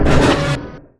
RbtTailbotAttack.wav